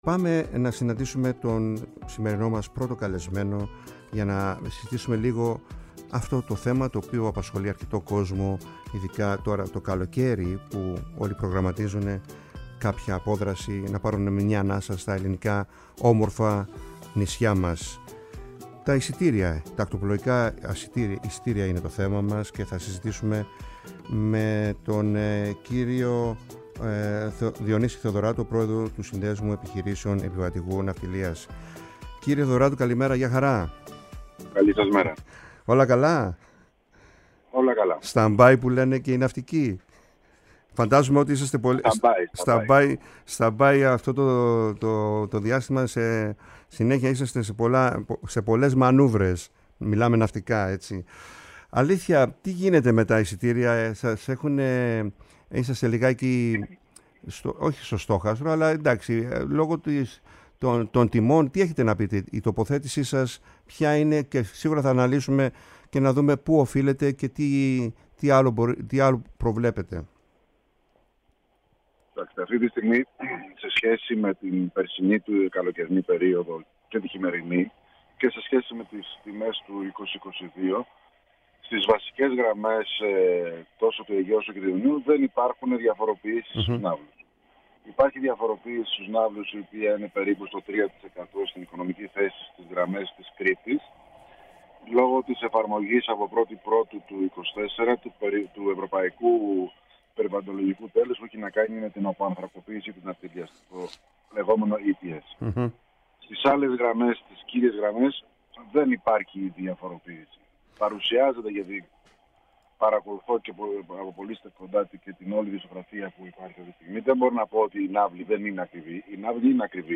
Μια πολύ ενδιαφέρουσα κουβέντα-συνέντευξη που σίγουρα θα συνεχιστεί και στα στούντιο της ΕΡΤ.